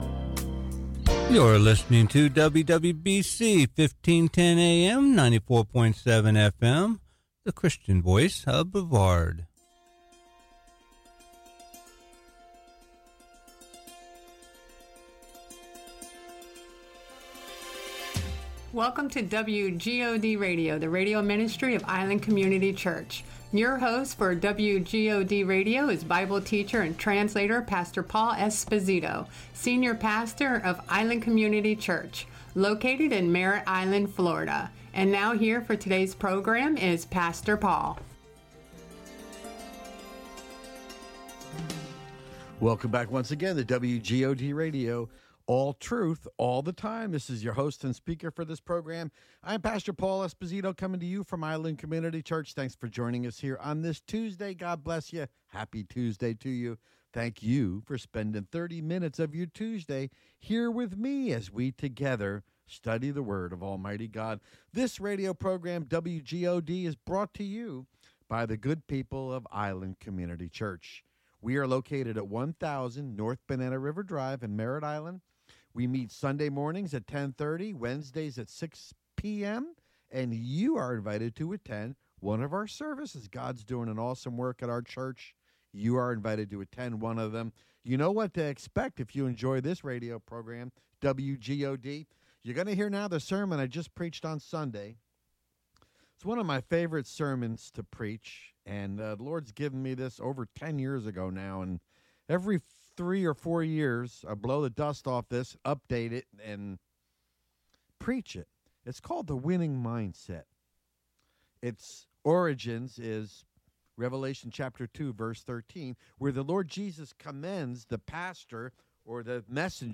Sermon "The Winning Mindset" Part 1